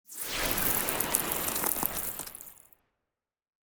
Free Frost Mage - SFX
freezing_gush_01.wav